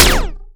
taser2.ogg